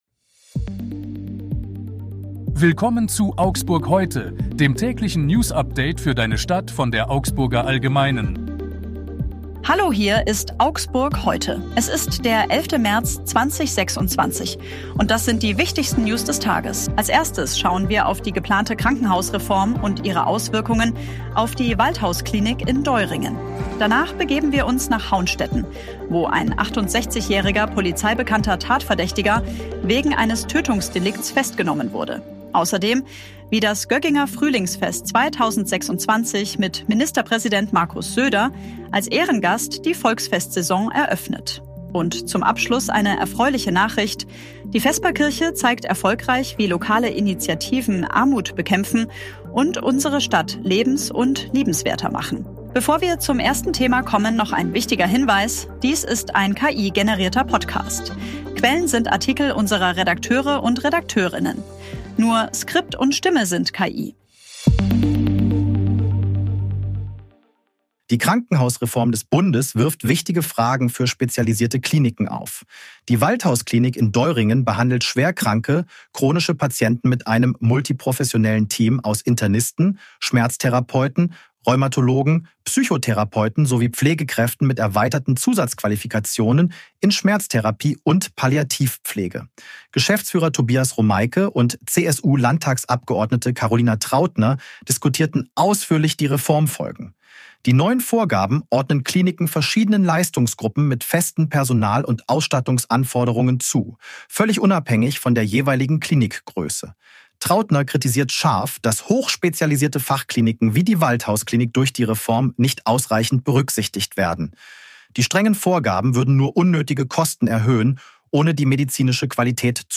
Nur Skript und Stimme sind